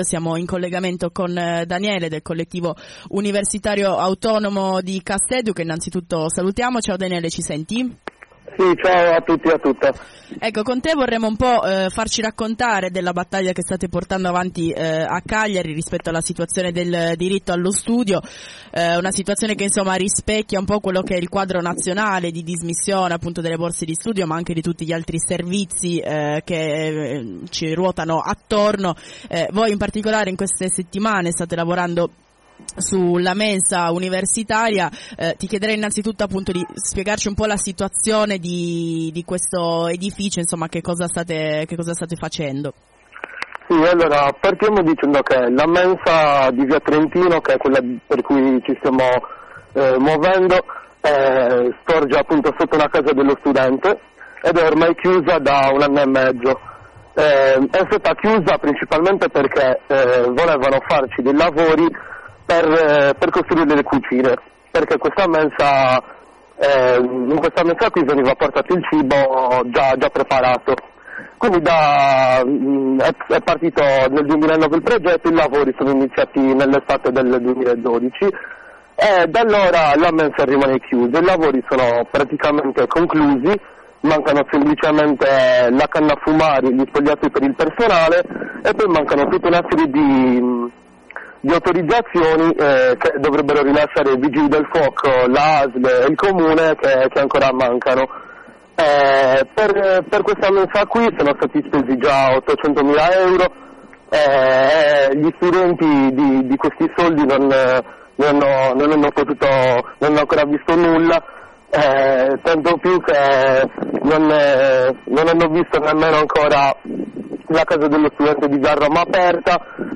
Intervento su Radio BlackOut 105.250 sulla questione della MENSA